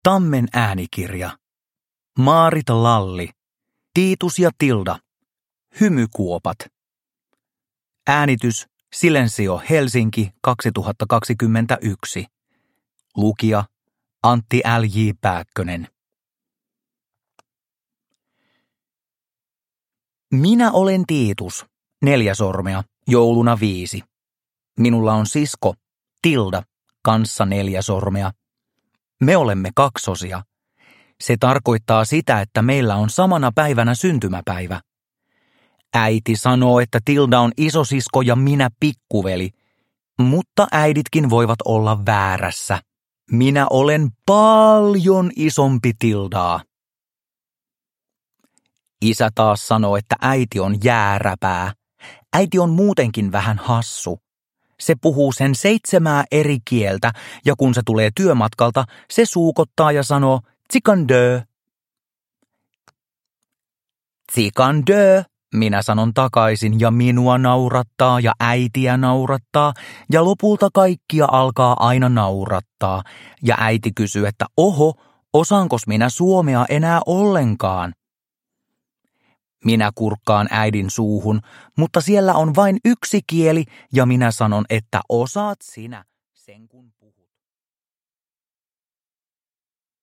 Produkttyp: Digitala böcker
Uppläsare: Antti L. J. Pääkkönen